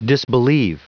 Prononciation du mot disbelief en anglais (fichier audio)
Prononciation du mot : disbelief